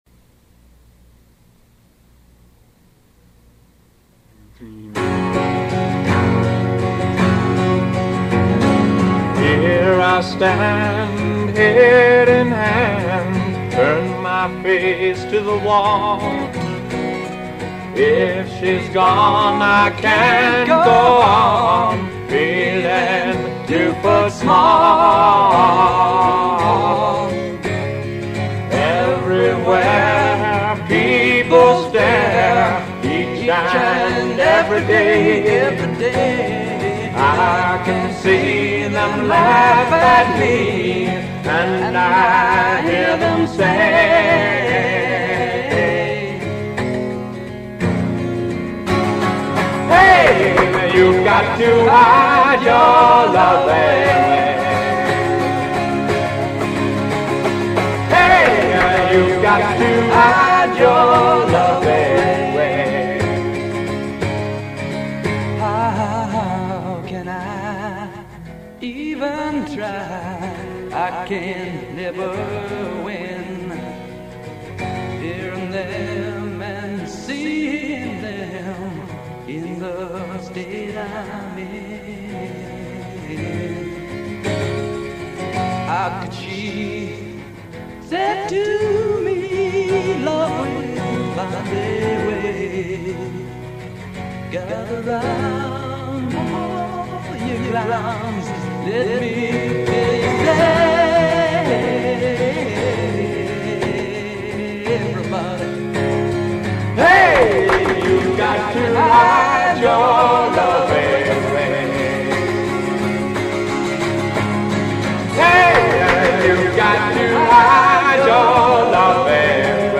Recording I produced in my bedroom circa 1969.
vocals